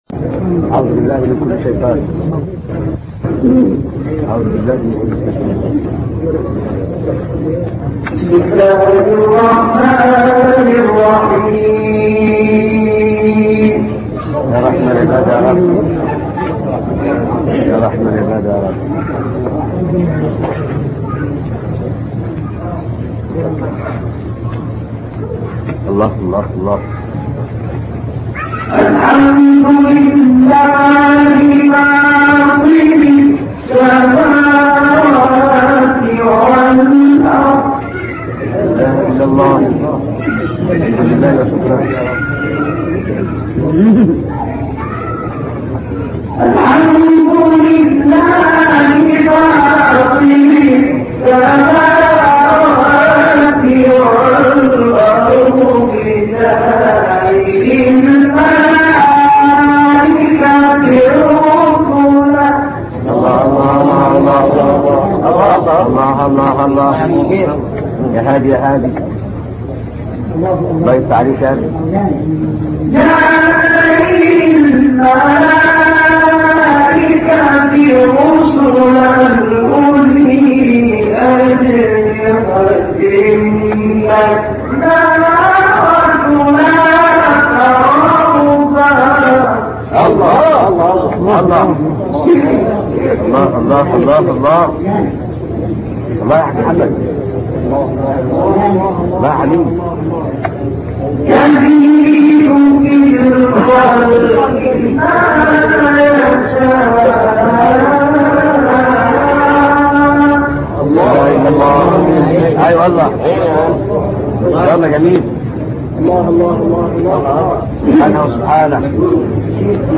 گروه شبکه اجتماعی: تلاوت آیاتی از سوره فاطر از محمد عبدالعزیز حصان که در مراسم عزای شیخ شکری حسن البرعی اجرا کرده است، می‌شنوید.
این تلاوت در روستای القباب الصغری در استان دقهلیه مصر و در سال 1981 میلادی اجرا شده است.